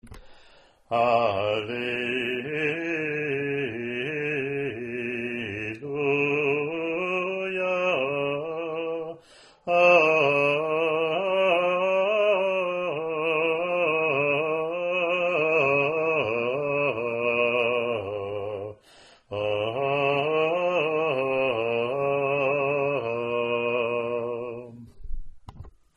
Alleluia Acclamation